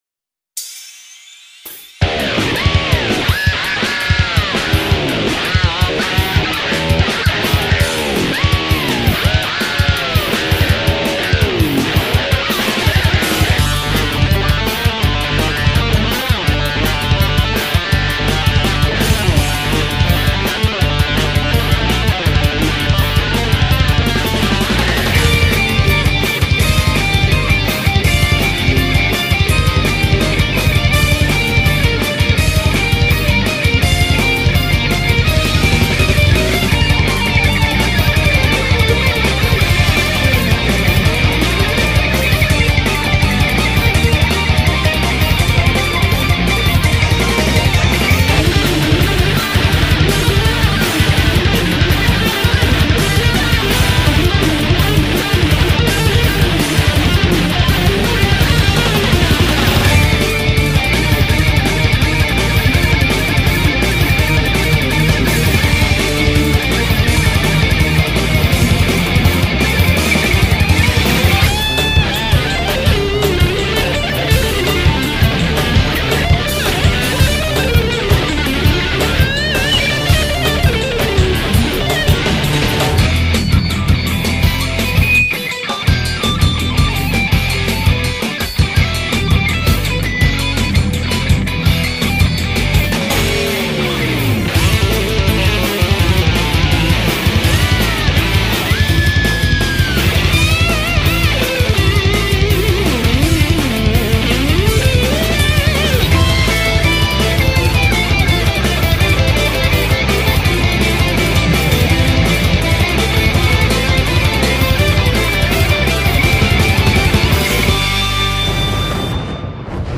BPM166
Audio QualityPerfect (High Quality)
An intense rock showdown